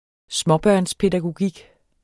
Udtale [ ˈsmʌbɶɐ̯ns- ]